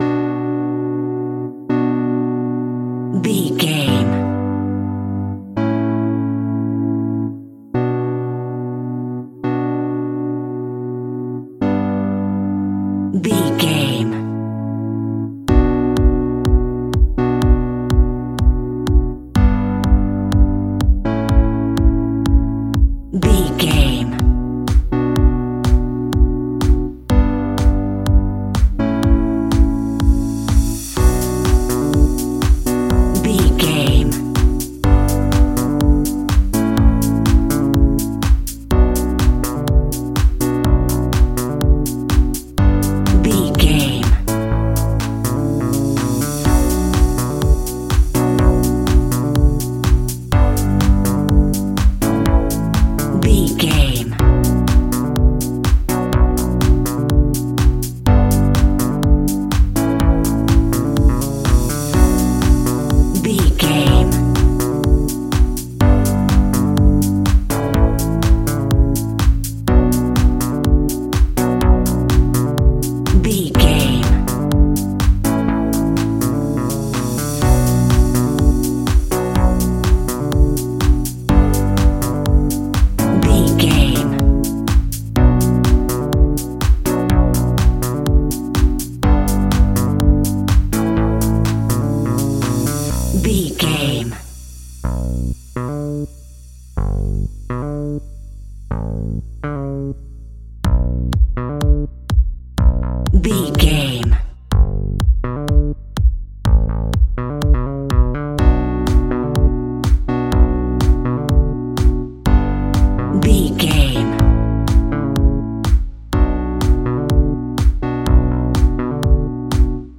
Aeolian/Minor
groovy
uplifting
driving
energetic
drum machine
synthesiser
house
electro house
funky house
synth leads
synth bass